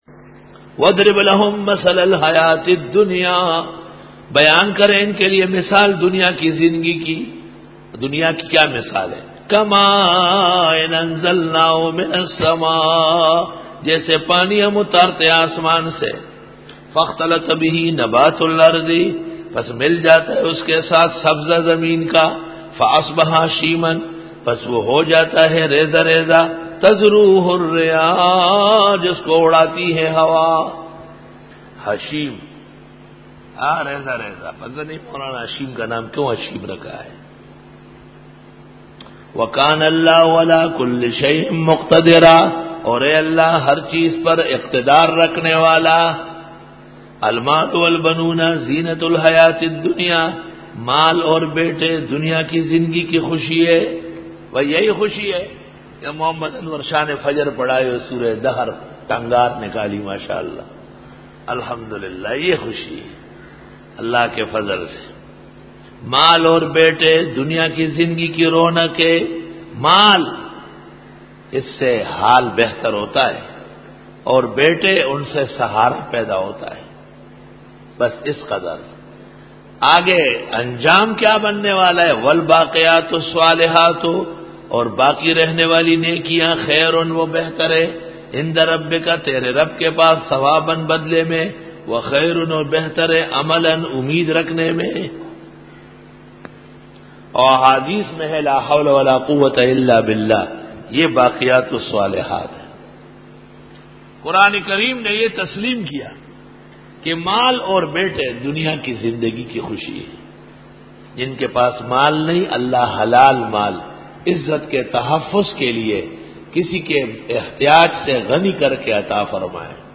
سورۃ الکھف رکوع-06 Bayan